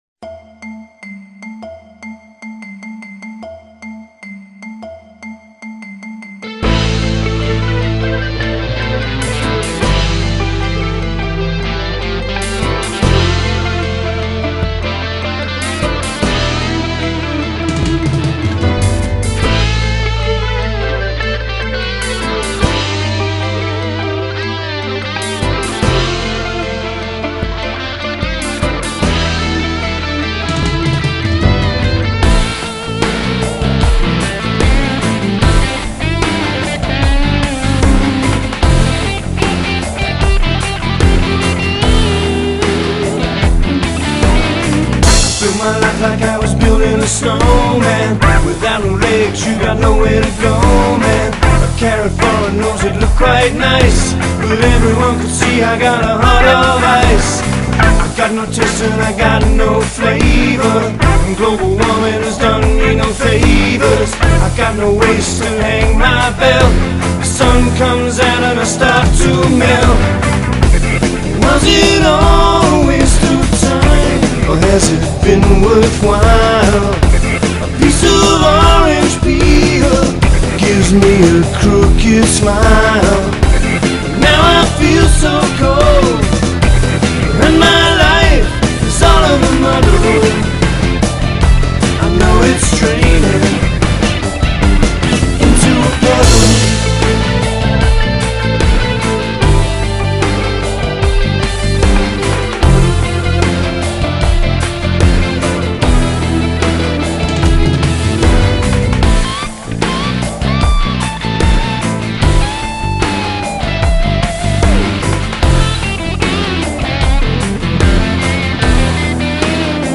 dance/electronic
recorded on to 8 track
Punk
Rock & Roll